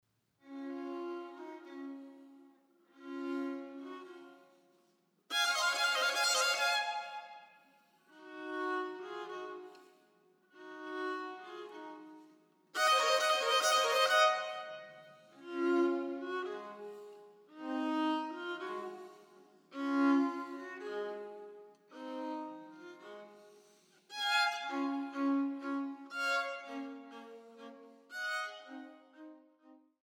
für zwei Violinen